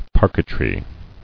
[par·quet·ry]